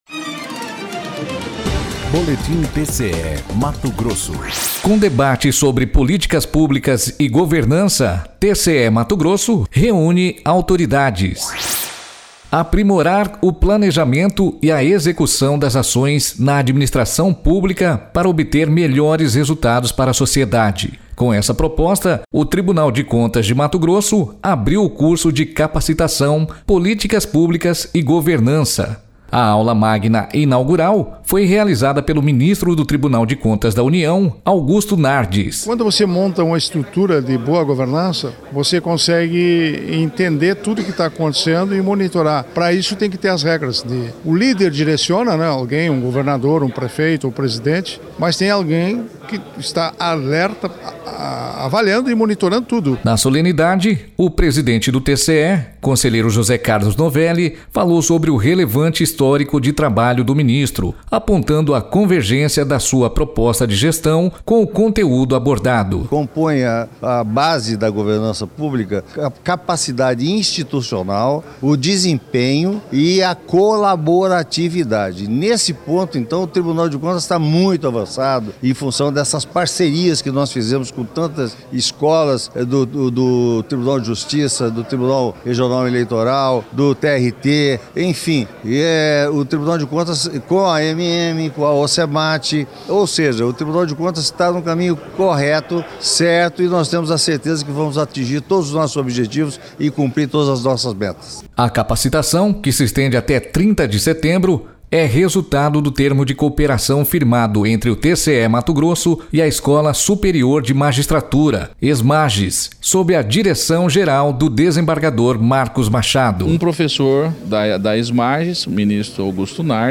Sonora: Augusto Nardes – ministro do TCU
Sonora: José Carlos Novelli – conselheiro presidente do TCE-MT
Sonora: Marcos Machado – desembargador diretor-geral da Esmagis
Sonora: Mauro Mendes – governador do Mato Grosso
Sonora: Wellington Fagundes – senador por MT